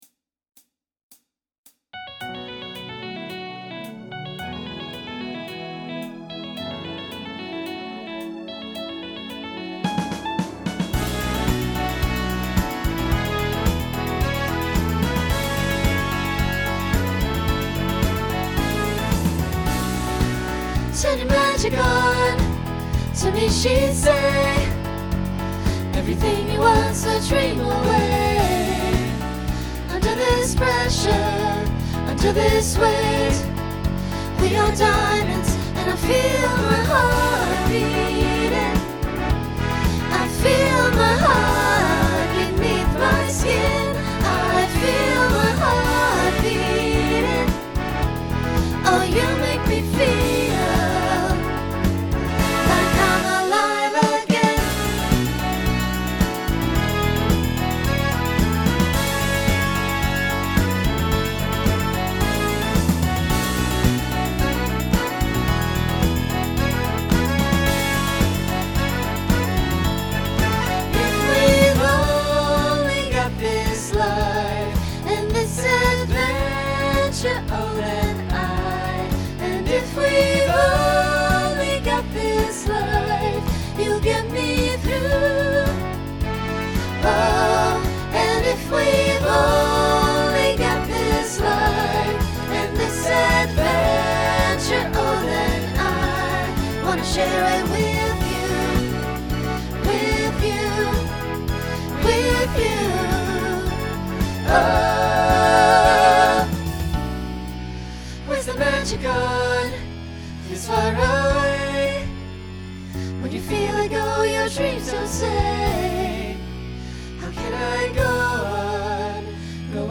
Genre Rock Instrumental combo
Mid-tempo , Opener Voicing SATB